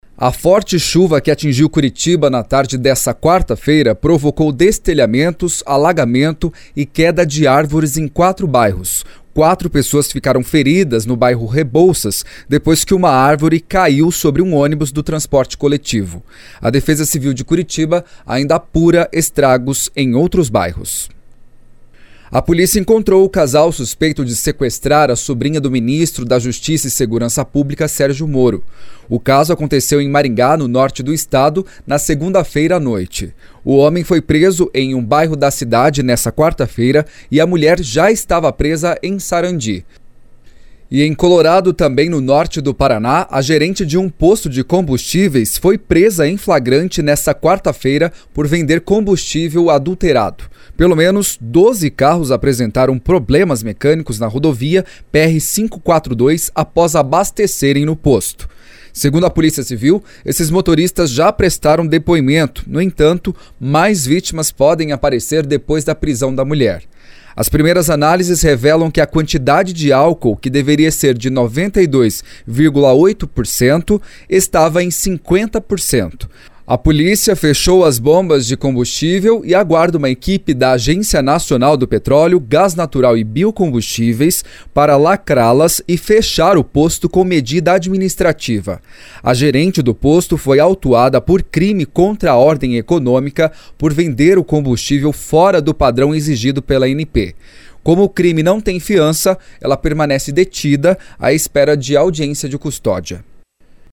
Giro de Notícias SEM TRILHA